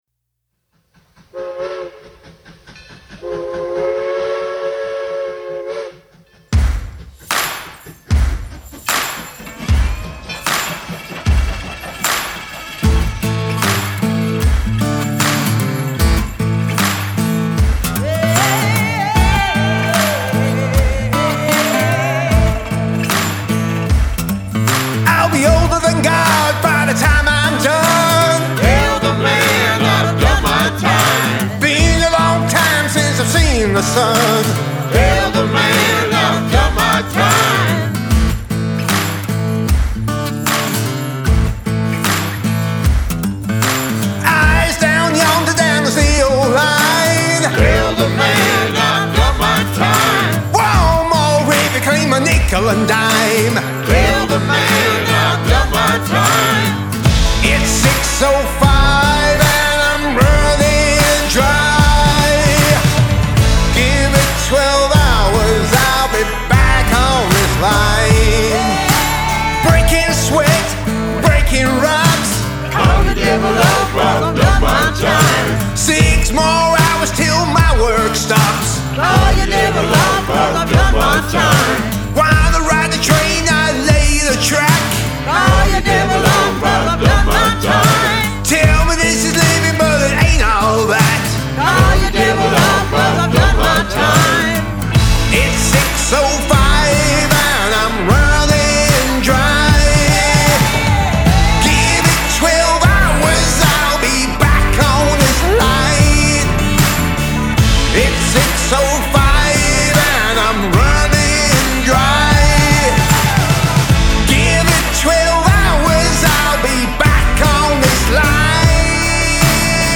Male Vocal, Guitar, Bass Guitar, Drums